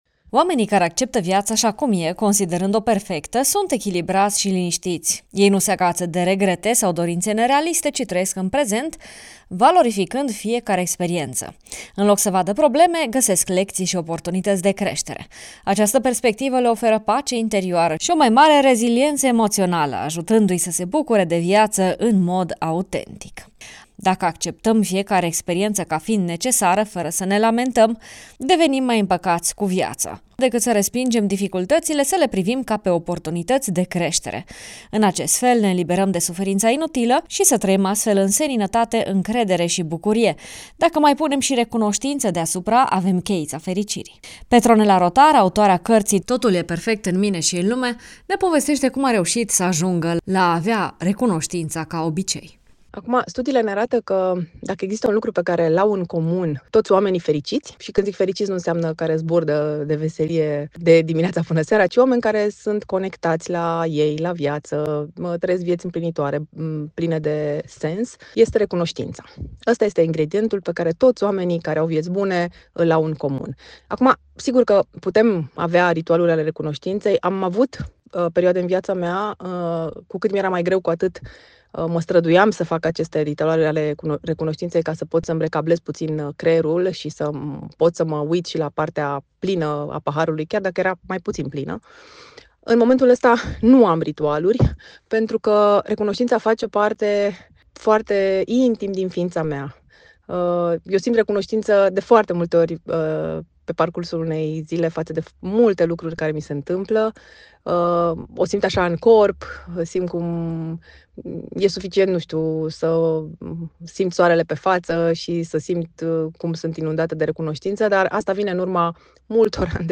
psiholog si autoare, ne povestește cum a reușit să ajungă la a  avea recunoștința ca obicei: